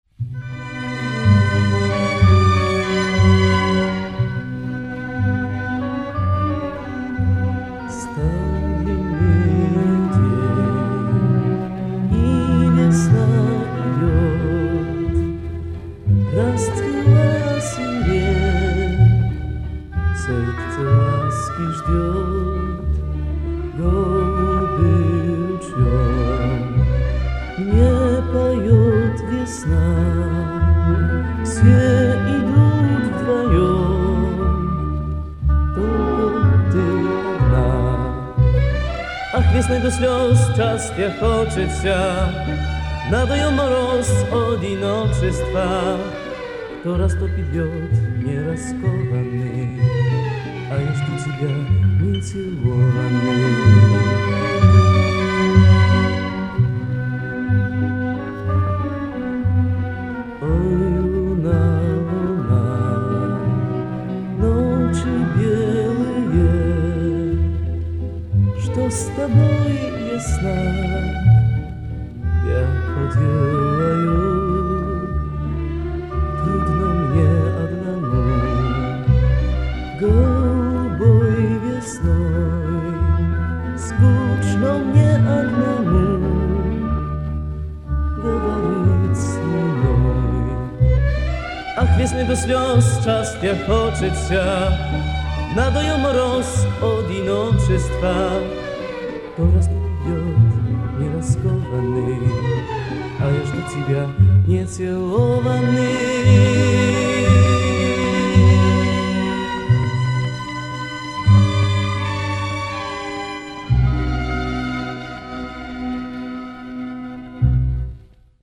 Запись с Международного фестиваля в Сочи.